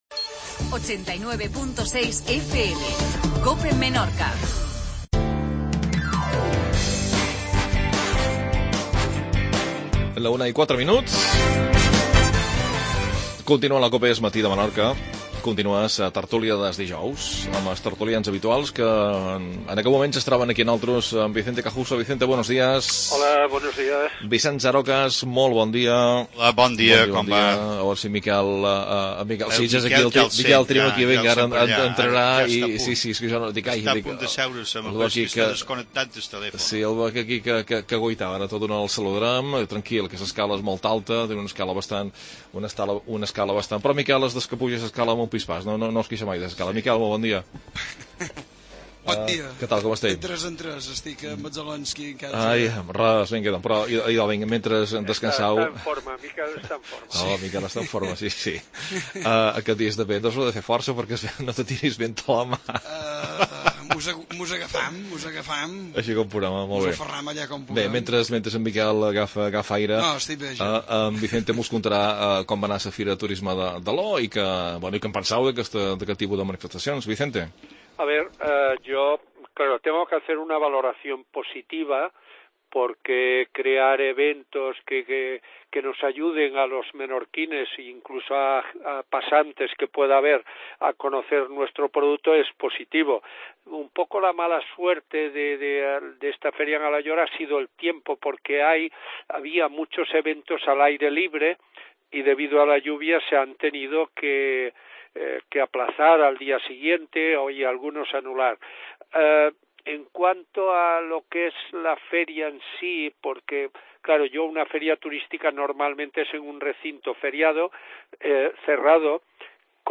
Tertulia. Visita agents de viatge francesos.